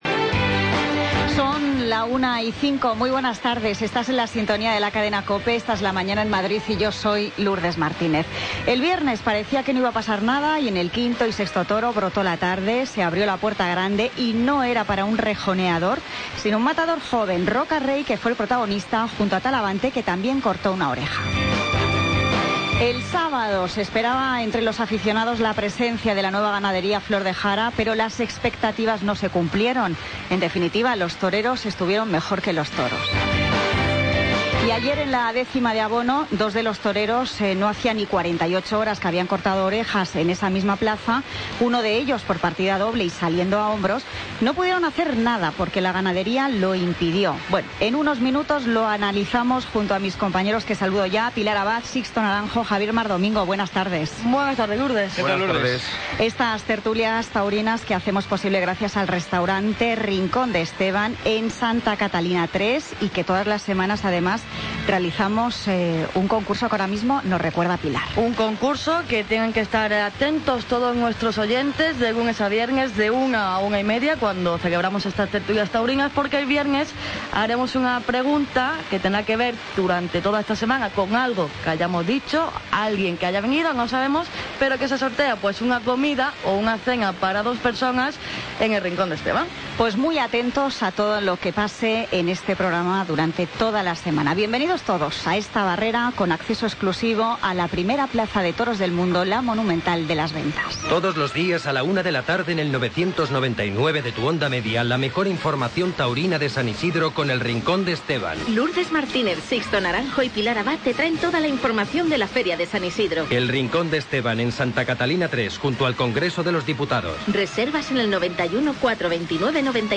Tertulia Taurina Feria San Isidro COPE Madrid, lunes 16 de mayo de 2016